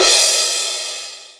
43_03_cymbal.wav